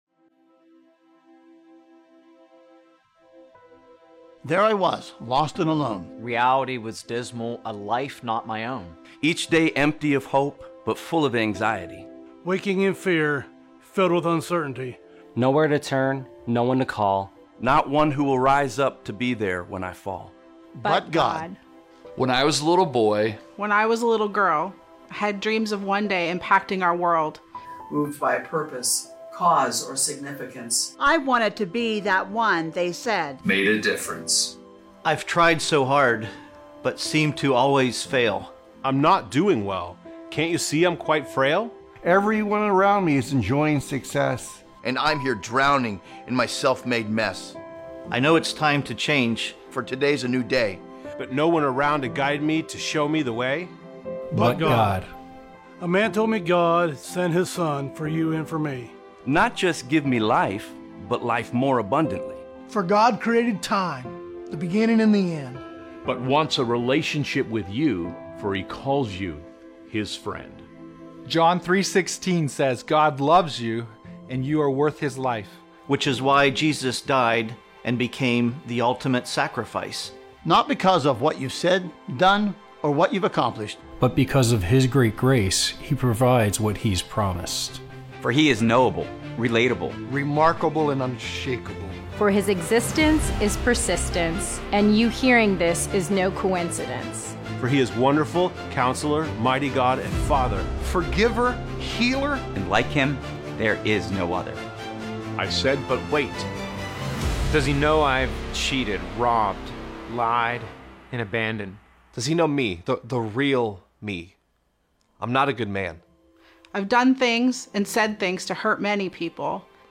Sermon Description
Join us for our first “virtual” Easter Service.